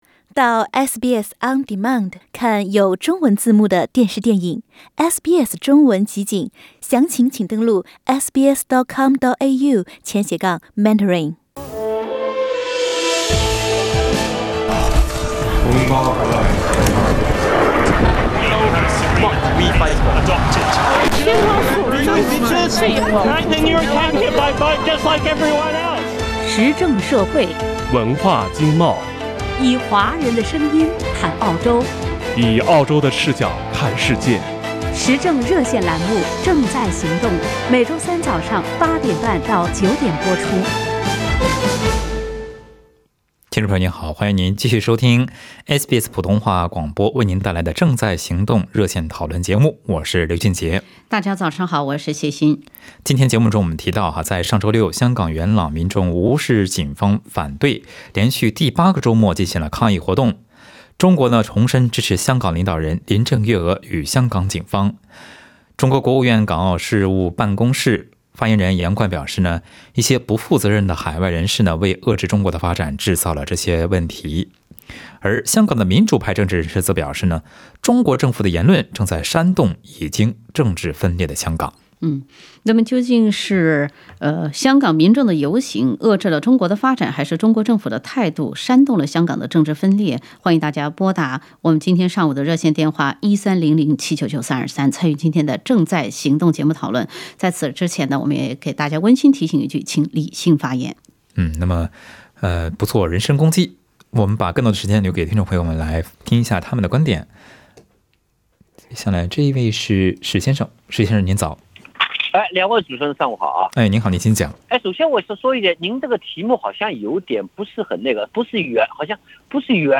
听众朋友们在本期《正在行动》节目中就此话题表达了观点。其中一位来自香港的人士说，那些没有上街游行的普通市民的心声也需要被倾听。